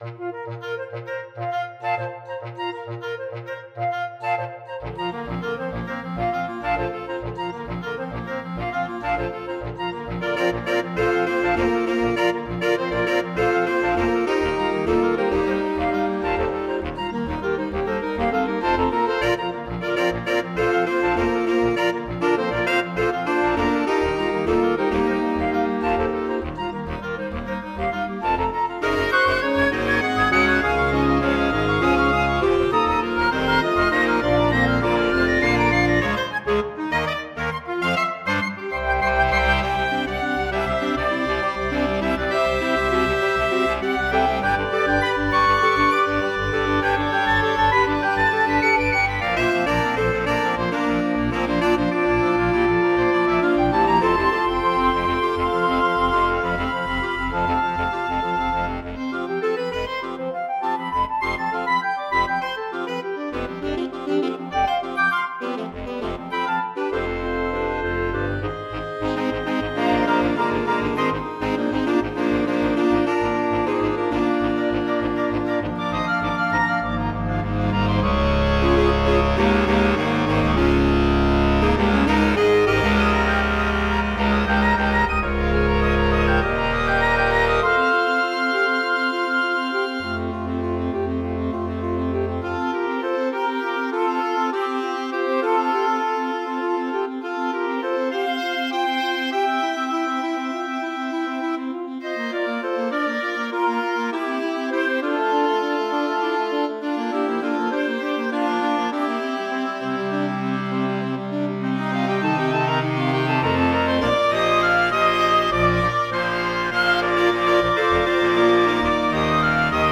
Voicing: Woodwind Ensemble